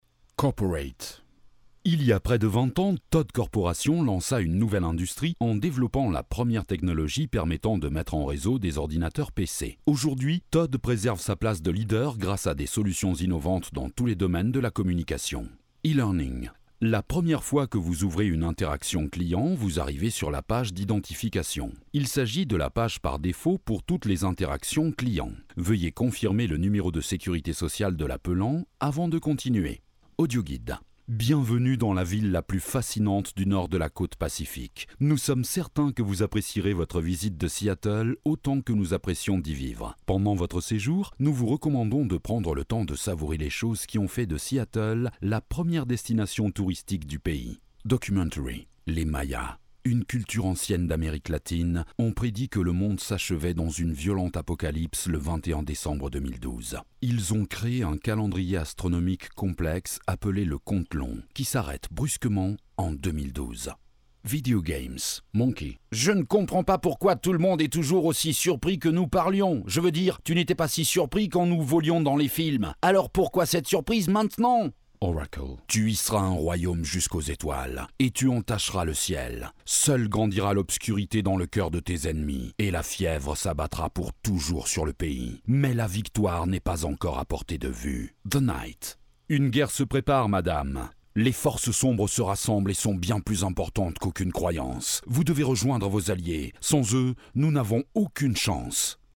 Voix homme grave 30-50 ans.
Sprechprobe: Sonstiges (Muttersprache):
Deep warm man voice for e-learning hold on messages audioguides commercials ..